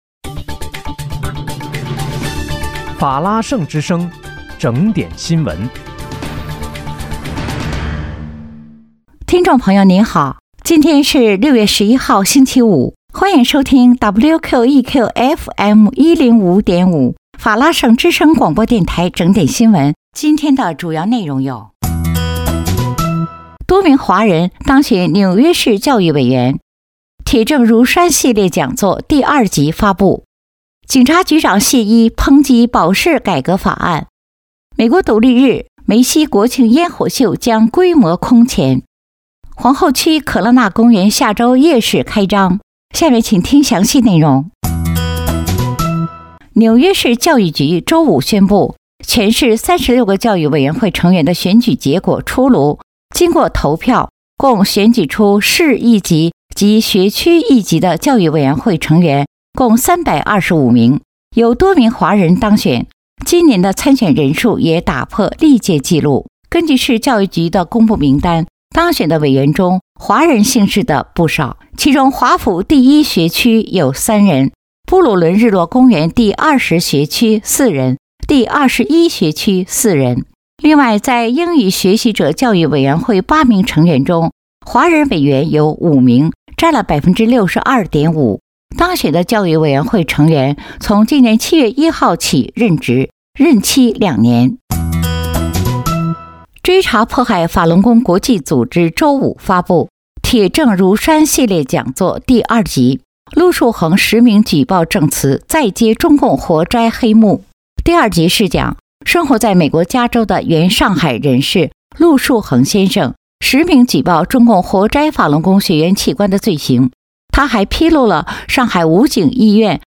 6月11日（星期五）纽约整点新闻